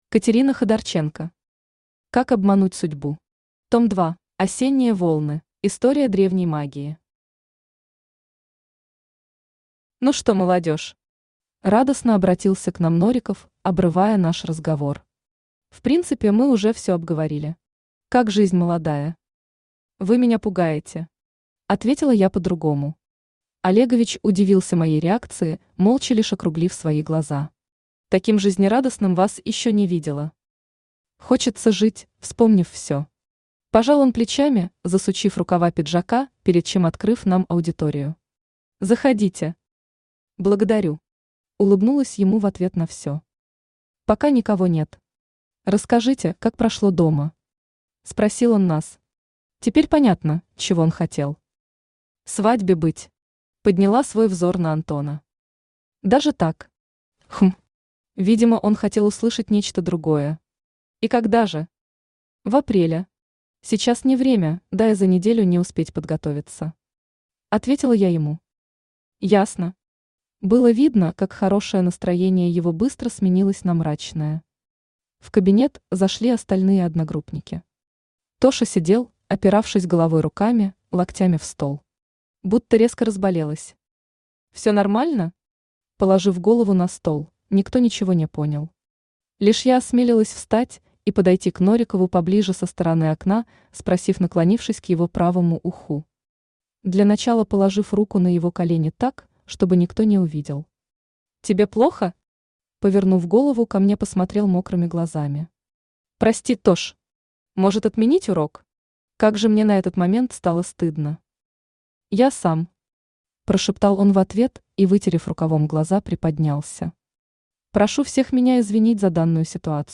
Аудиокнига Как обмануть Судьбу. Том 2 | Библиотека аудиокниг
Читает аудиокнигу Авточтец ЛитРес.